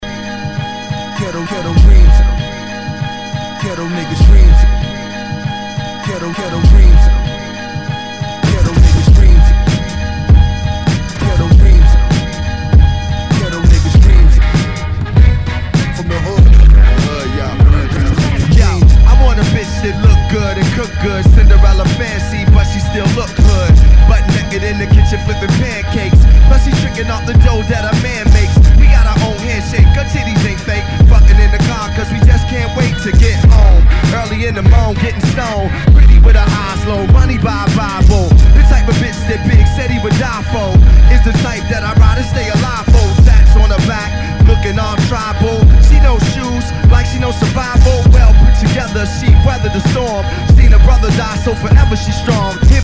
Tag       EASTCOAST 　 HIP HOP